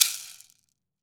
WOOD SHAKER7.WAV